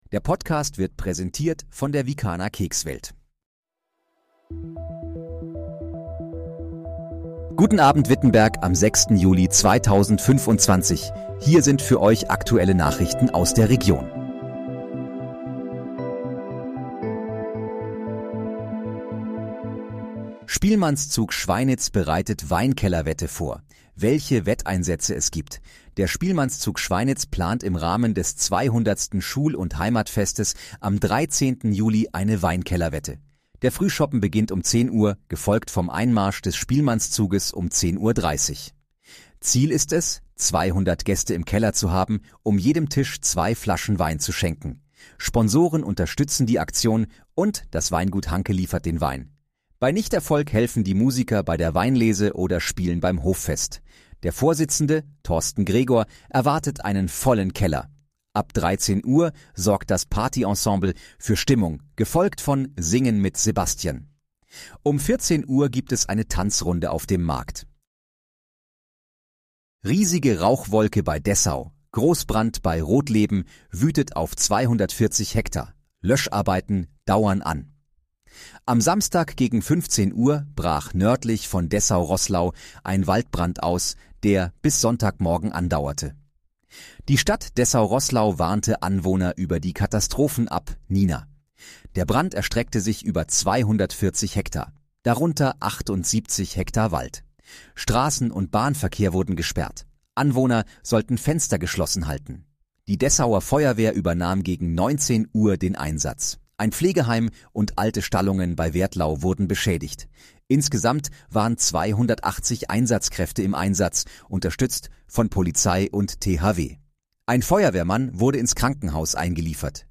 Guten Abend, Wittenberg: Aktuelle Nachrichten vom 06.07.2025, erstellt mit KI-Unterstützung
Nachrichten